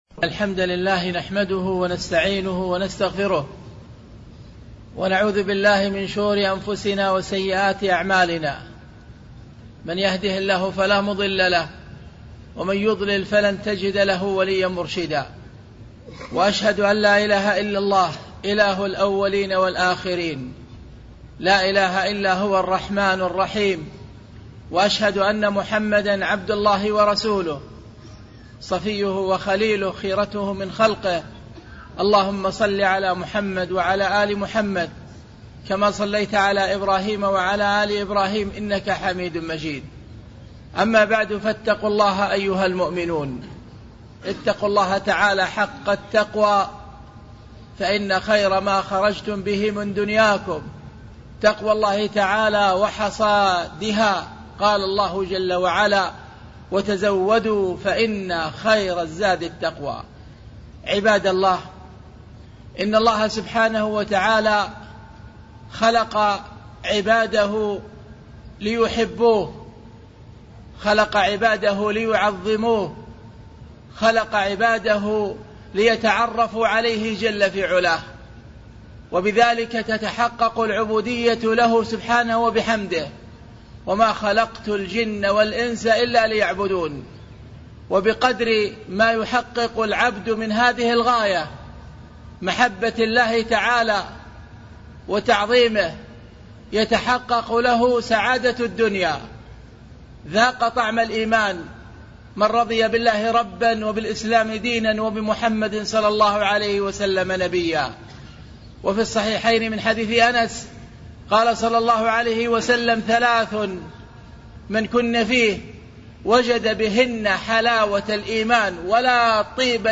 خطبة - أأنت حاسد أو محسود